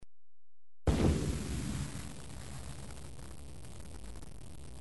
دانلود آهنگ بمب و موشک 3 از افکت صوتی حمل و نقل
دانلود صدای بمب و موشک 3 از ساعد نیوز با لینک مستقیم و کیفیت بالا
جلوه های صوتی